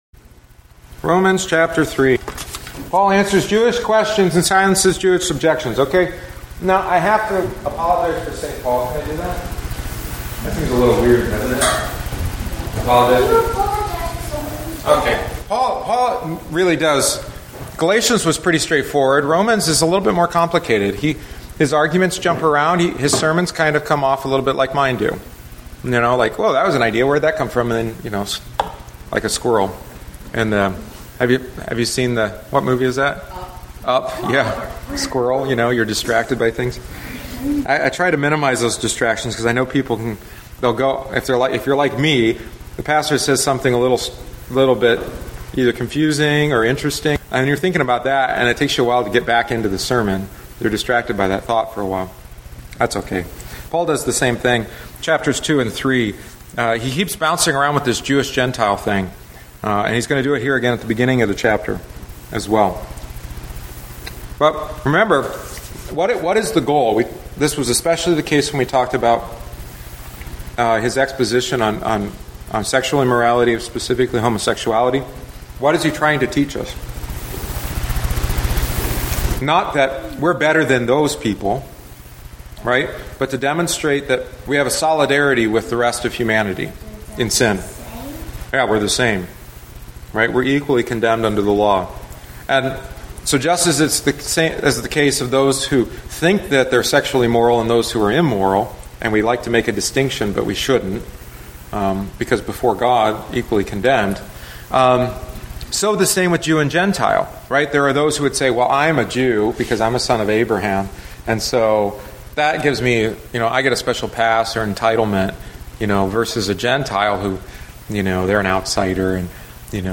The following is the seventh week’s lesson.